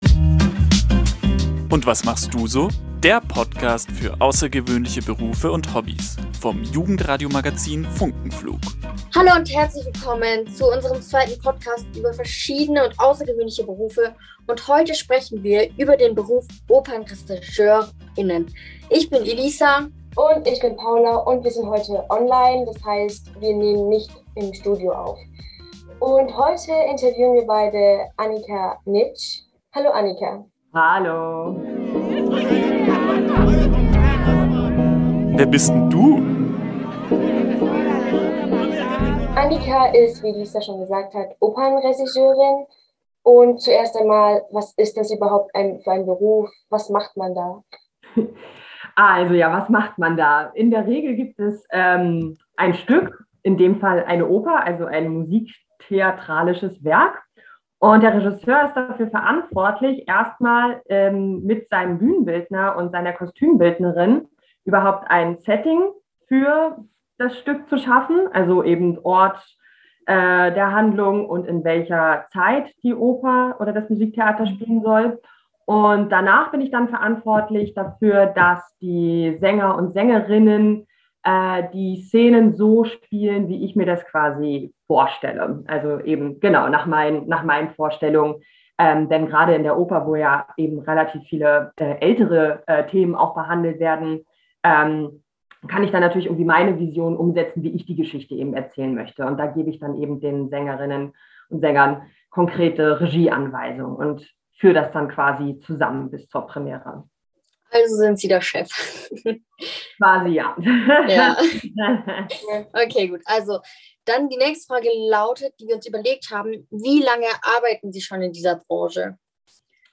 Episode_02_Interview_Opernregisseurin_fertig.mp3